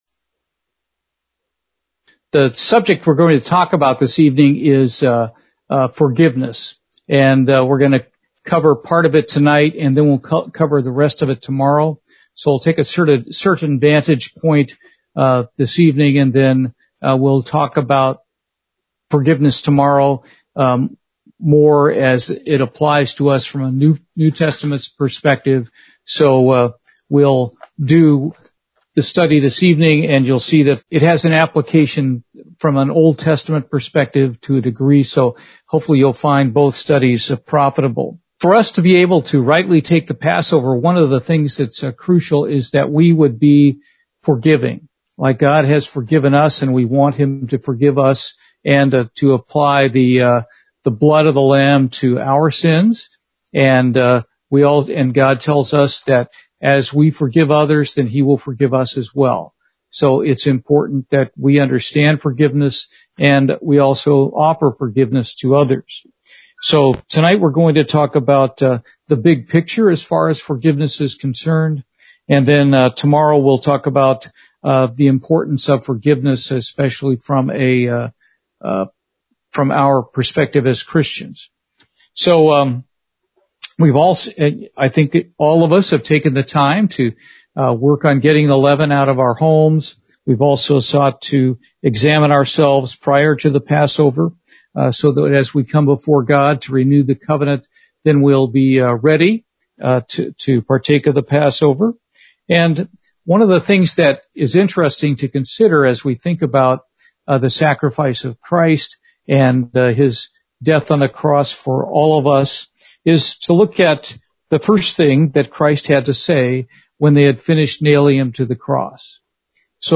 This is the first of a two part message on forgiveness.